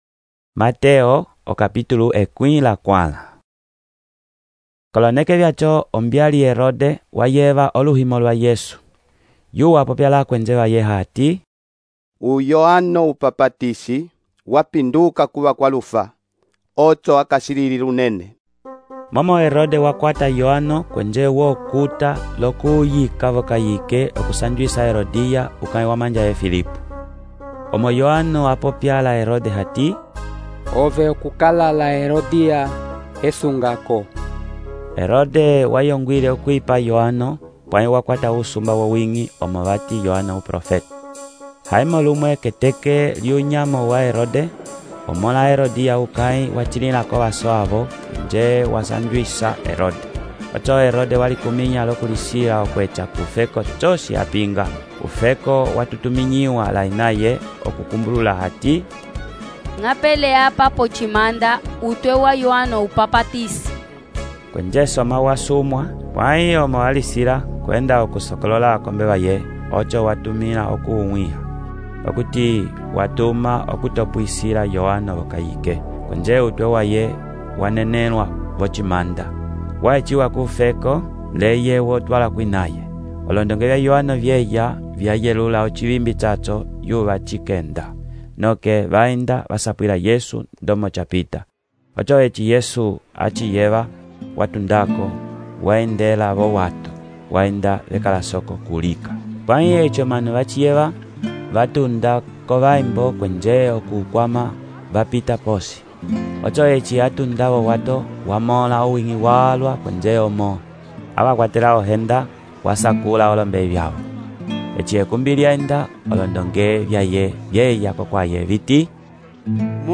texto e narração , Mateus, capítulo 14